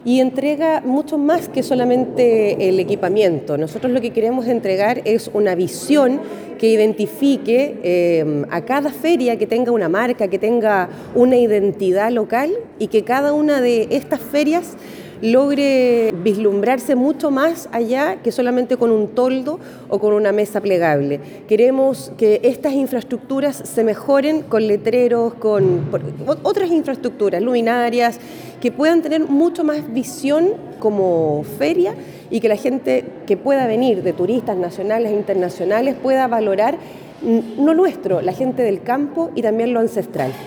Al respecto, la consejera regional Andrea Iturriaga comentó que se trata de una iniciativa que entre sus propósitos busca entregar identidad a las diferentes ferias, más allá de la entrega un toldo o una mesa plegable, y que así los turistas nacionales e internacionales las pueda reconocer fácilmente.